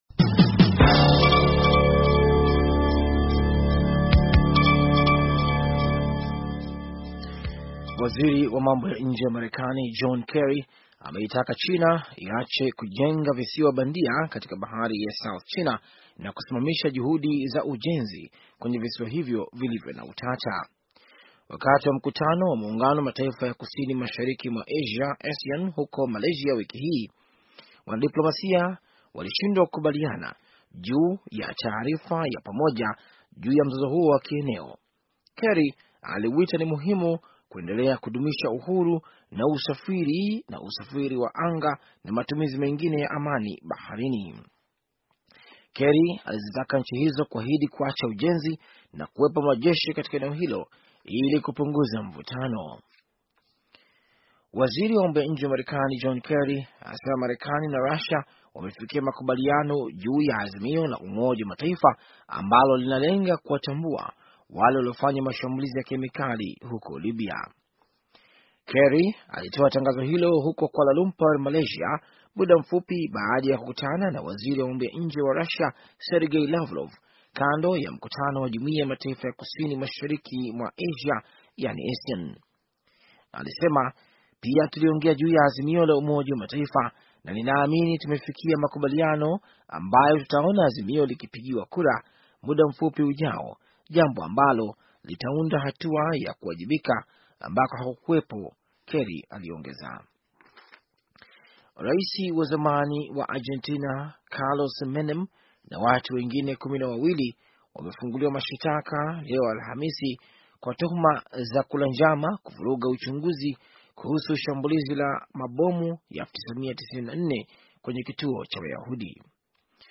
Taarifa ya habari - 4:43